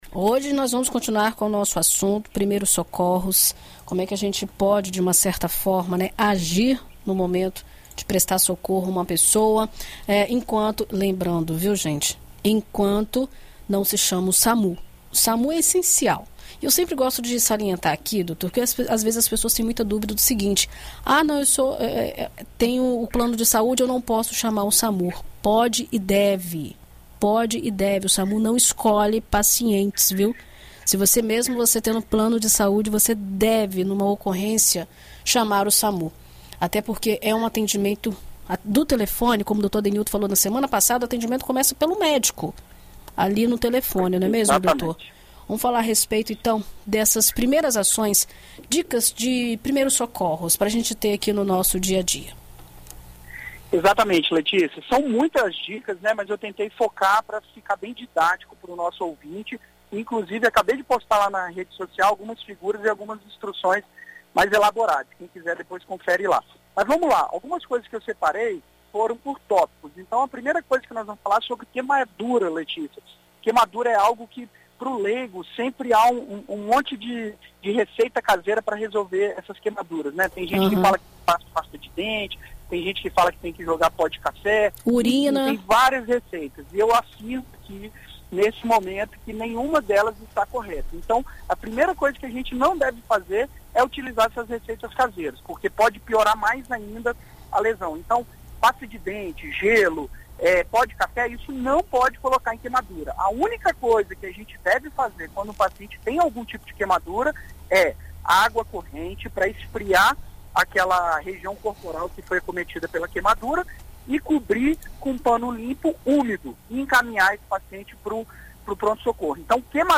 Na coluna Visita Médica desta quinta-feira (18), na BandNews FM Espírito Santo